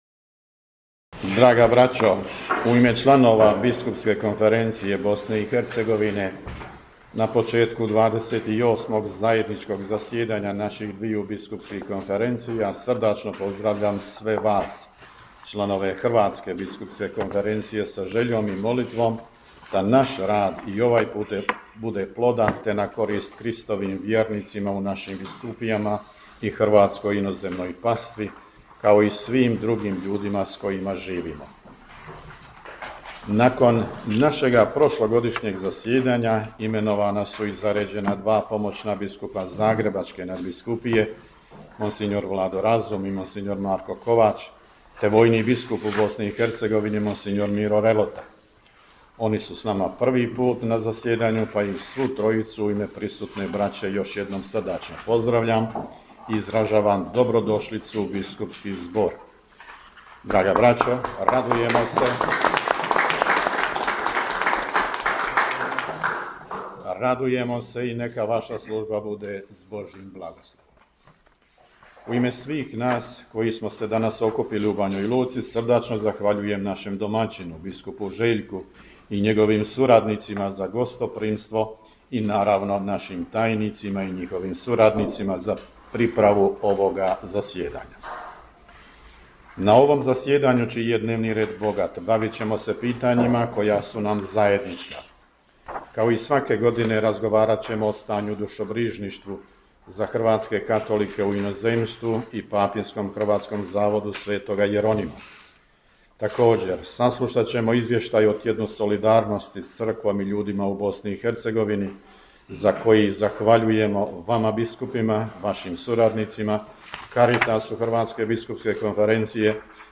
U Banjoj Luci započelo zajedničko zasjedanje biskupa BK BiH i HBK
Na samom početku zasjedanja pozdravne govore uputili su predsjednik BK BiH, vrhbosanski nadbiskup mons. Tomo Vukšić i predsjednik HBK, zagrebački nadbiskup mons. Dražen Kutleša.